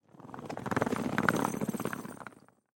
Звуки чемодана
Шум чемодана, катящегося по полу аэропорта